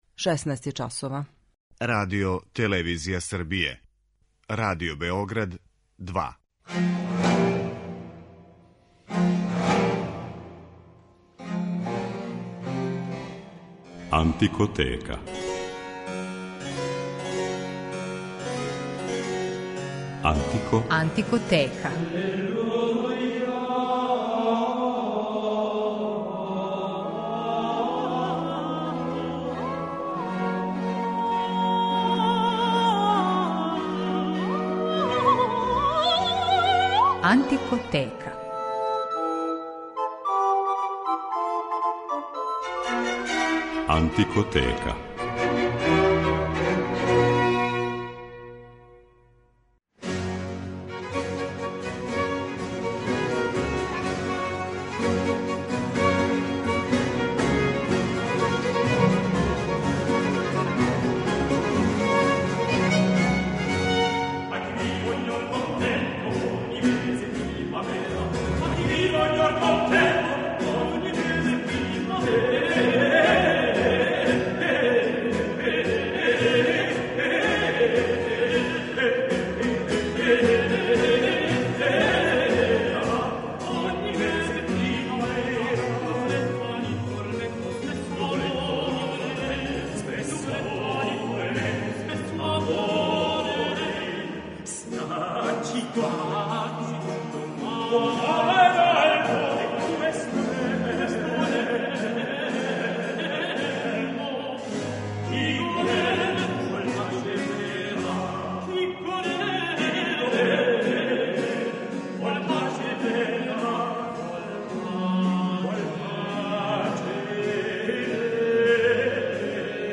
Један од најистакнутијих представника италијанског вокално-инструментaлног стила прве половине 17. века био је Луиђи Роси.
води слушаоце у свет ране музике и прати делатност уметника специјализованих за ову област који свирају на инструментима из епохе или њиховим копијама.